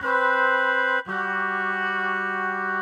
GS_MuteHorn_85-CG.wav